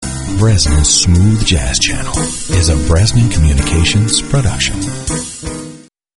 Radio Imaging & Voiceover